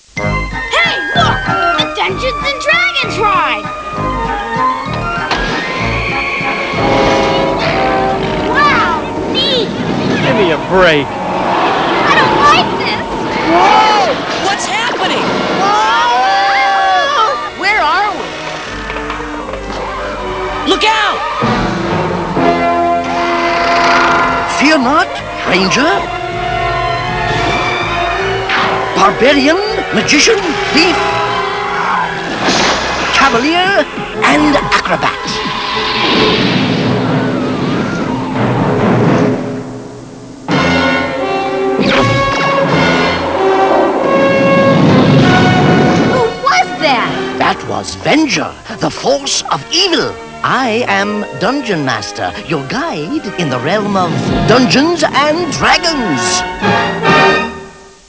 theme music